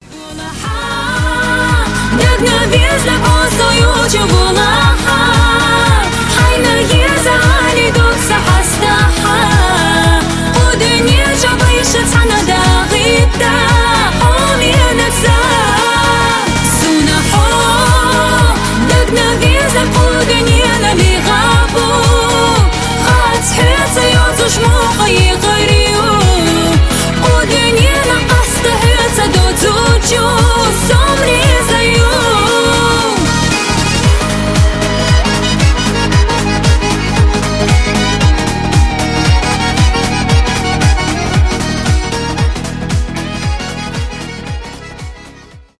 поп
красивый женский голос